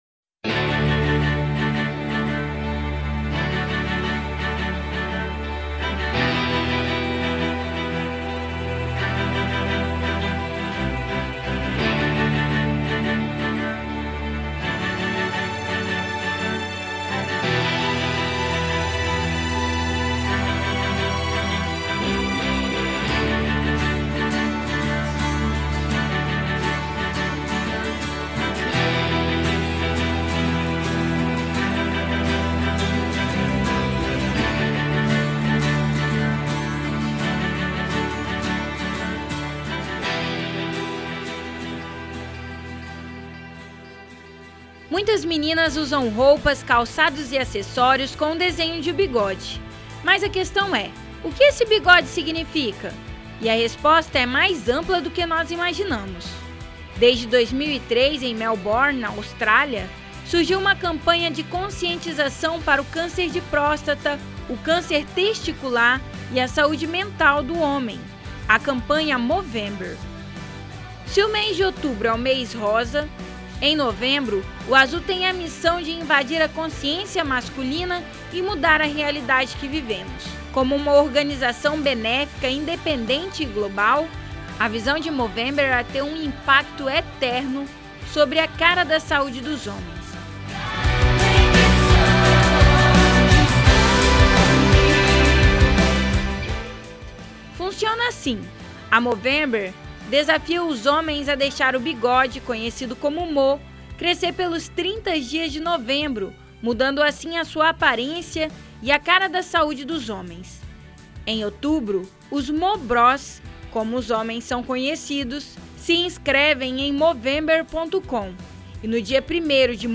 Confira a matéria do Revista Universitária!